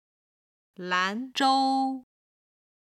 今日の振り返り！中国語発声
兰州　(Lán zhōu)　蘭州